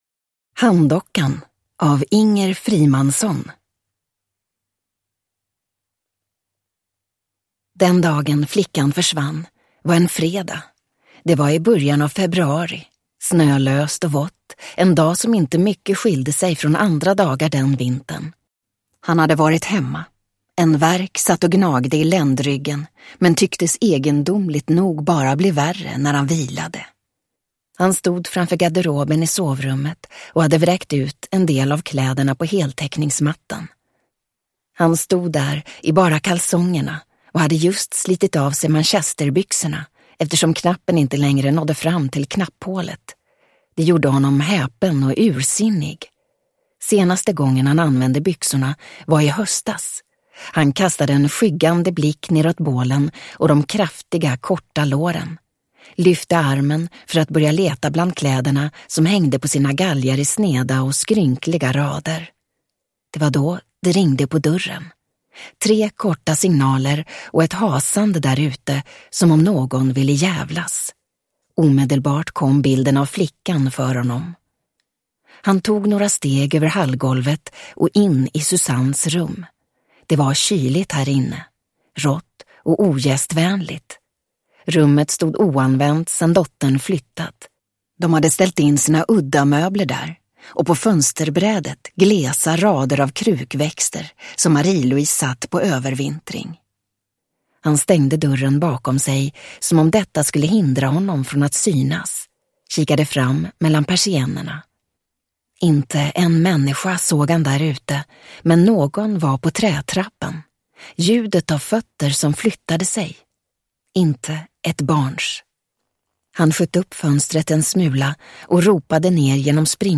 Handdockan – Ljudbok – Laddas ner